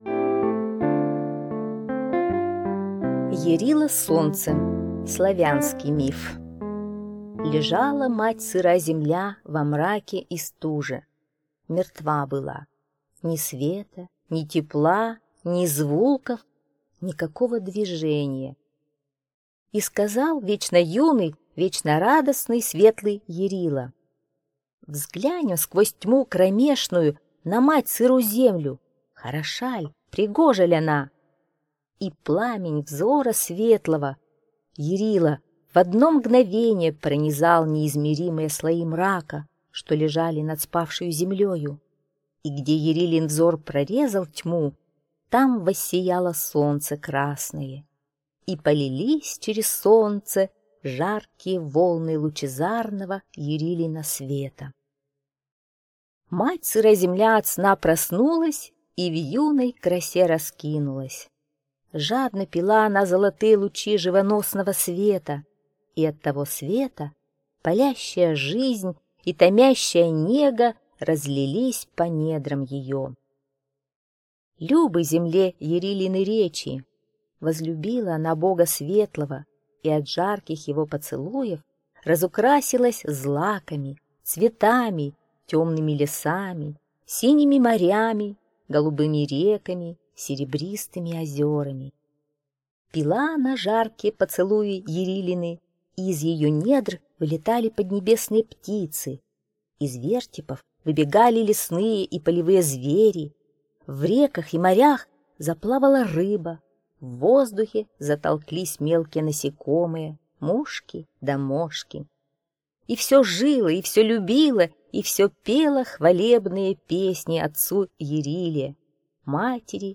Аудиосказка «Ярило-Солнце»